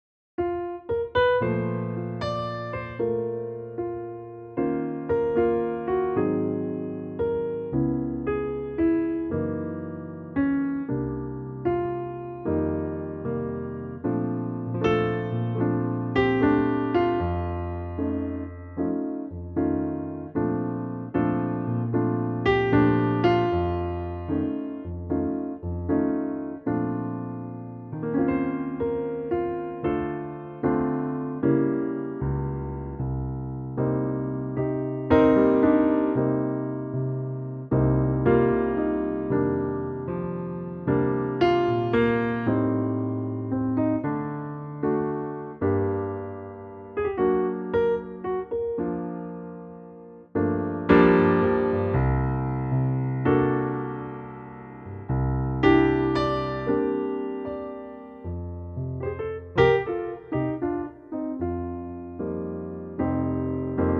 key Bb
key - Bb - vocal range - D to A (huge vocal range)